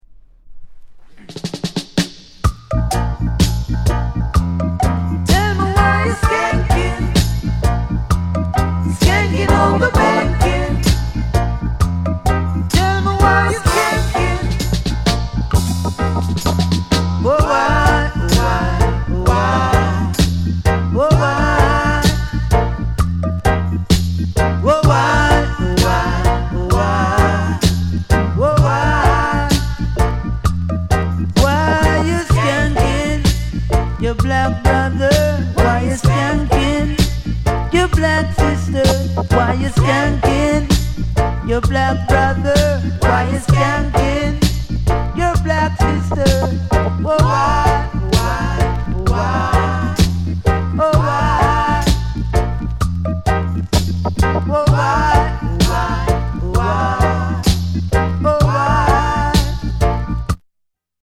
WICKED INST DRUM SONG